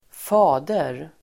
Uttal: [²f'a:der]